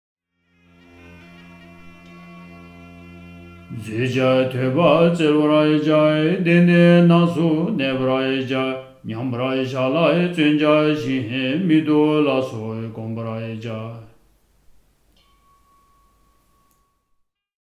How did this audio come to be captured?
SSC_Verse_Recording_Verse_20_with_music.mp3